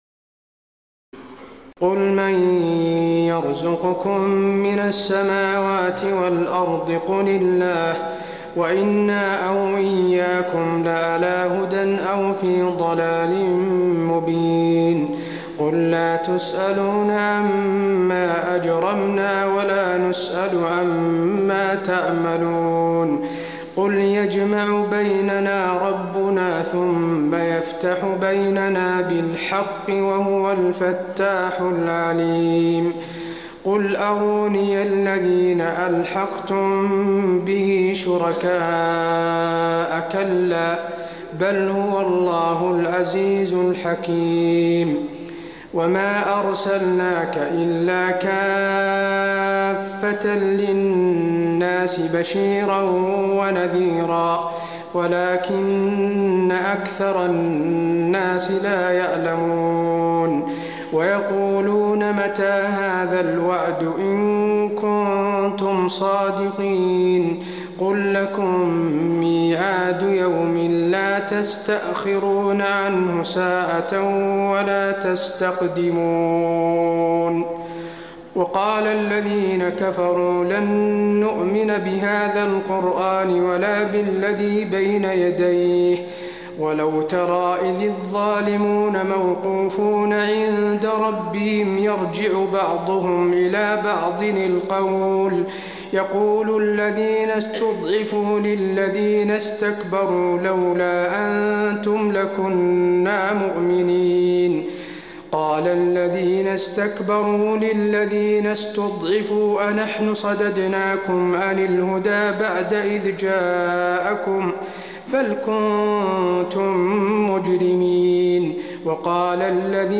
تراويح ليلة 21 رمضان 1426هـ من سور سبأ (24-54) و فاطر كاملة Taraweeh 21 st night Ramadan 1426H from Surah Saba and Faatir > تراويح الحرم النبوي عام 1426 🕌 > التراويح - تلاوات الحرمين